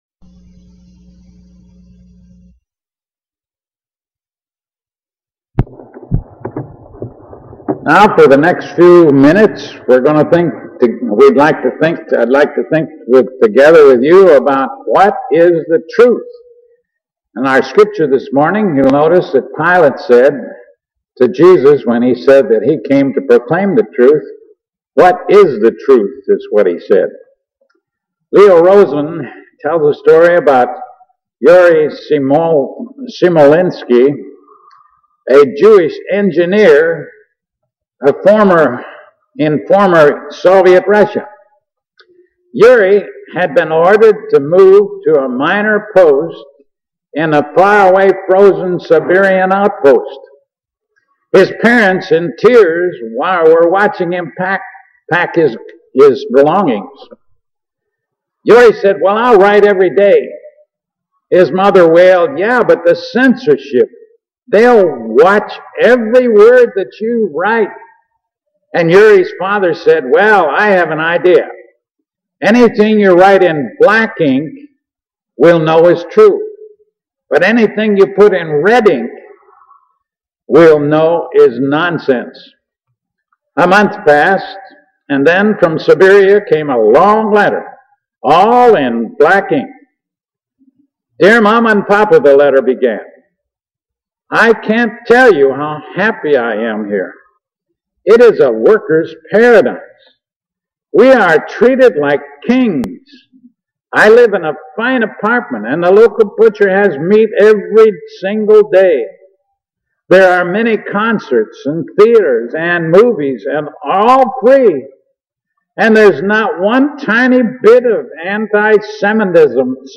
John 18:38 What Is Truth Filed Under: All Christian Sermons , Bible